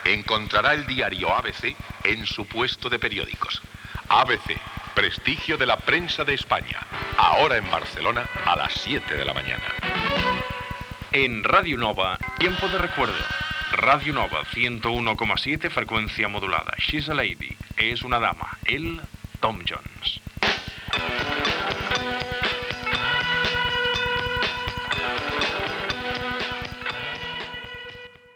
Publicitat, identificació de l'emissora i del programa i tema musical.
Musical
FM